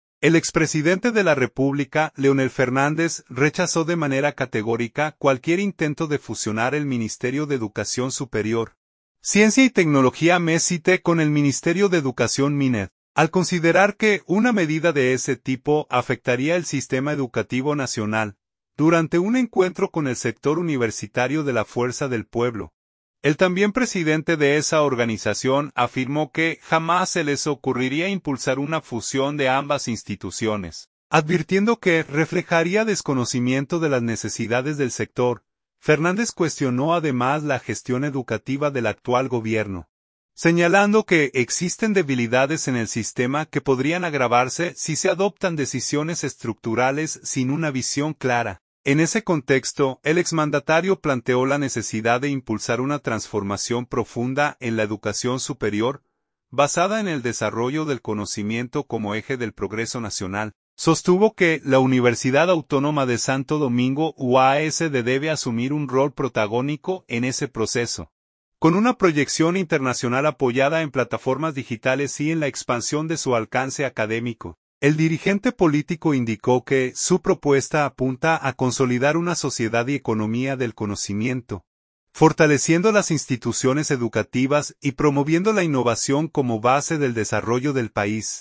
Durante un encuentro con el sector universitario de la Fuerza del Pueblo, el también presidente de esa organización afirmó que “jamás se les ocurriría” impulsar una fusión de ambas instituciones, advirtiendo que reflejaría desconocimiento de las necesidades del sector.